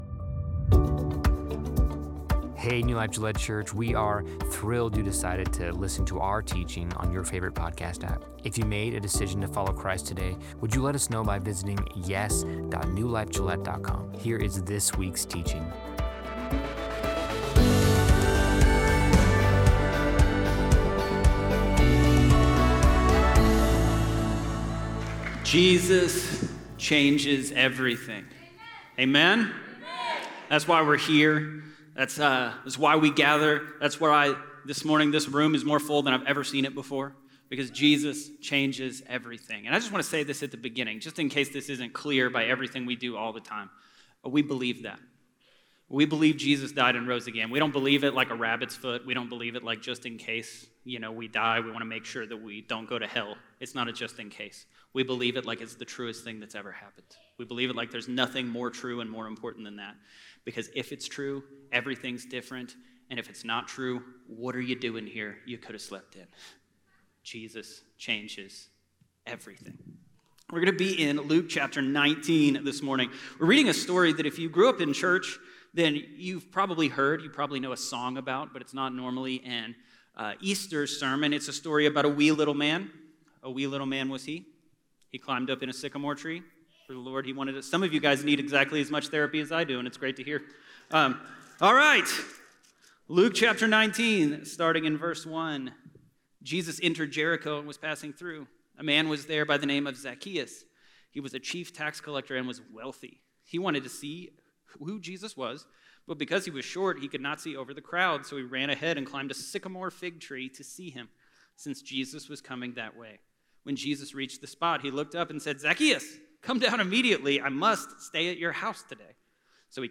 Easter changes everything.